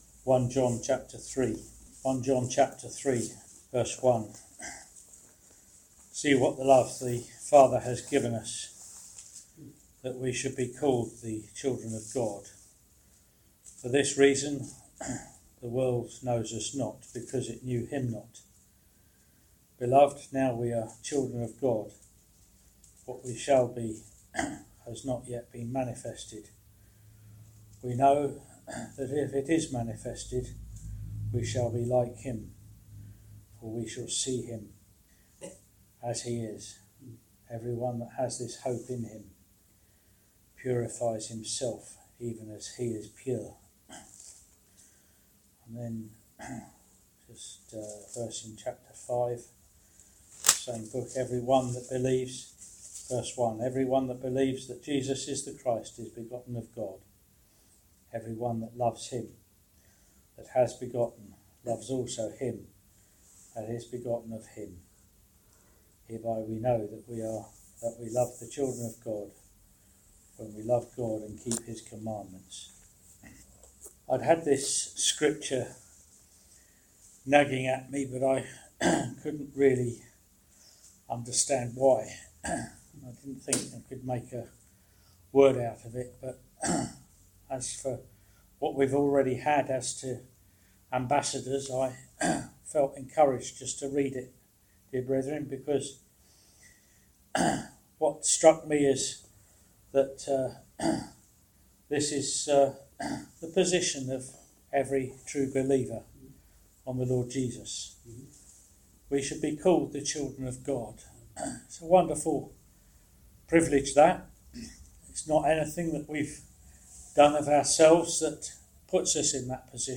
The title the Children of God is given to believers in the Lord Jesus. In this short ministry word you will hear of what the believer is brought into in Christ.